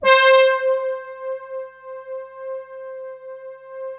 Index of /90_sSampleCDs/Best Service ProSamples vol.55 - Retro Sampler [AKAI] 1CD/Partition D/BRASS PAD